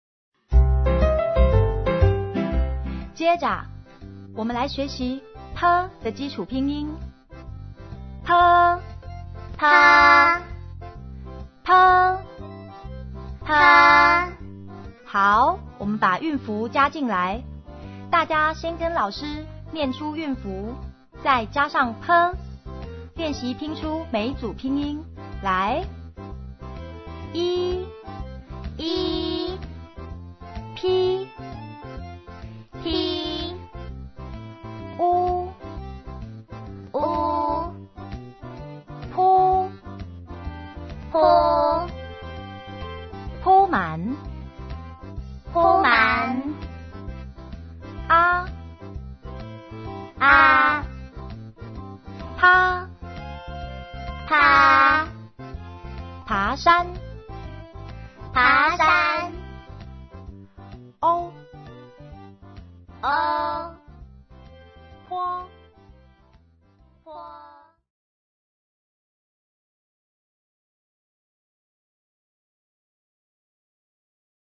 ◎CD裡附有「拼音帶念」「兒歌唱遊」